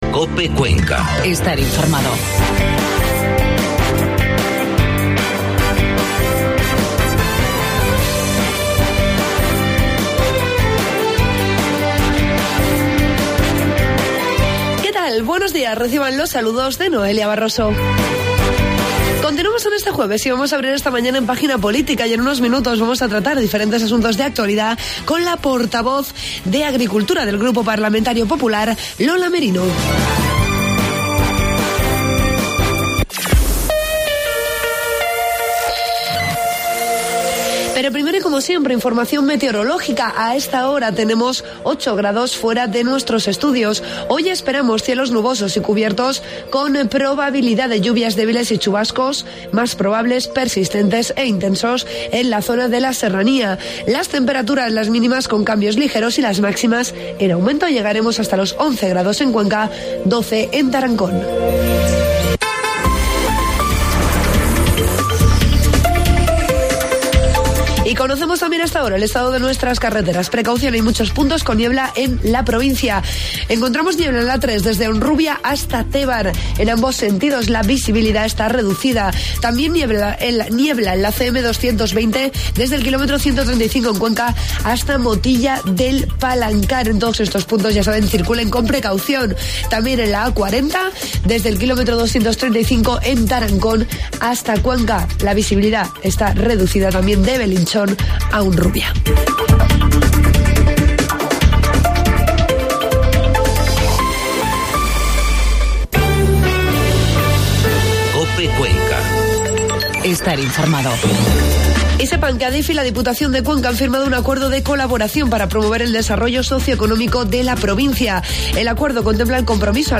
Entrevista a la portavoz de Agricultura del Grupo Parlamentario Popular, Lola Merino.